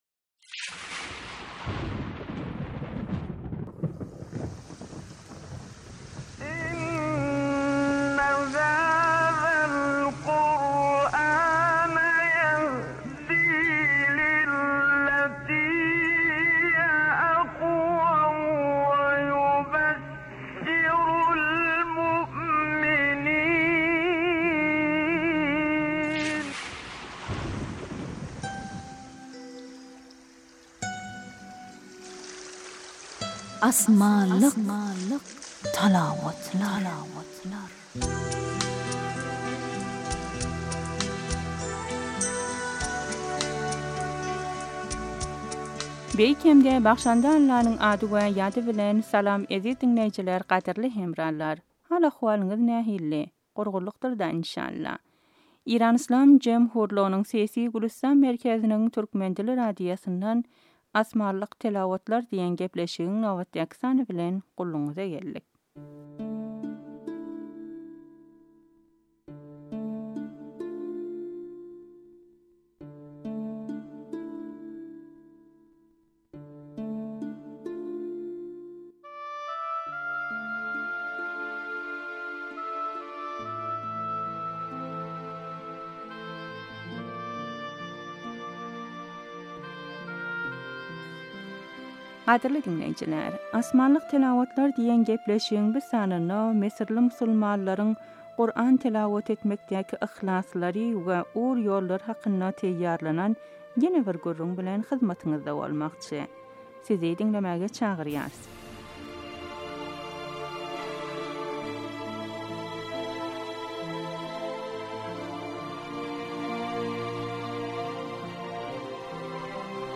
Asmanlik talawatlar : müsürli musulmanlaryň telawat etmekleri